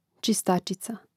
čistàčica čistačica im. ž. (G čistàčicē, DL čistàčici, A čistàčicu, V čistàčice, I čistàčicōm; mn.